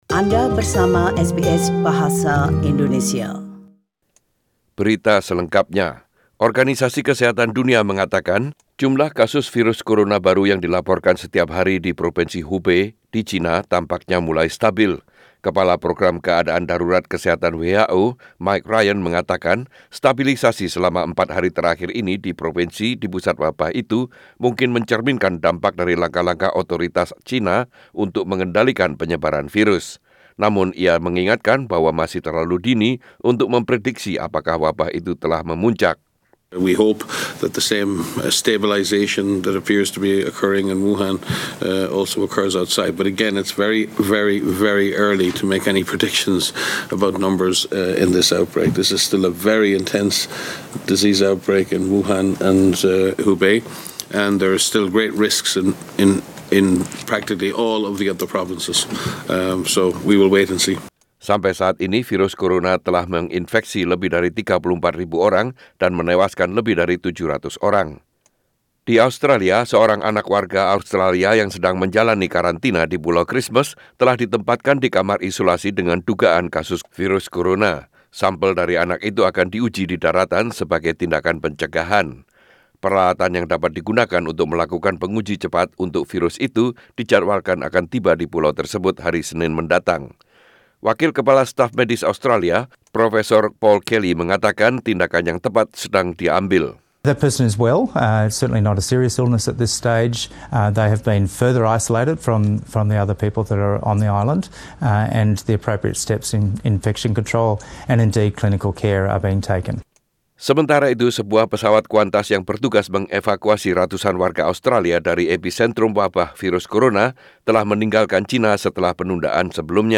SBS Radio News in Indonesian - 09/02/2020